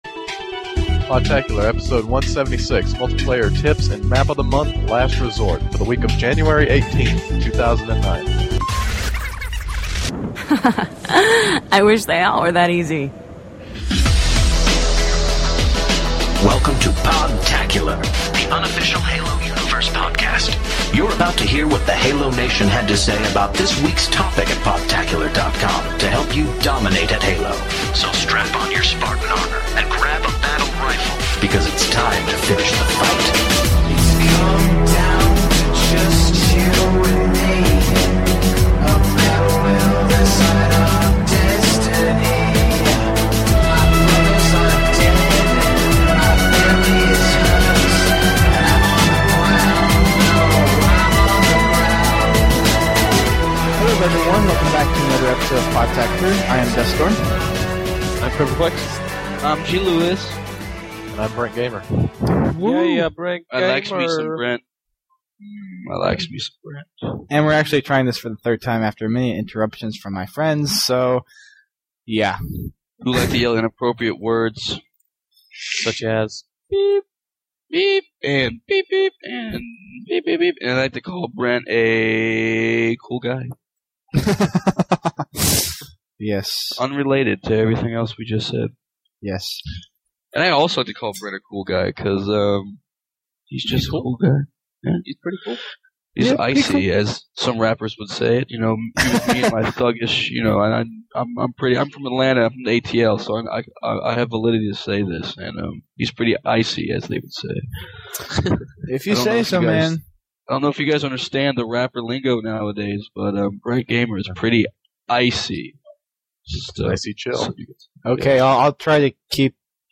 Be sure to check out the bloopers as I get continually interrupted by my friends.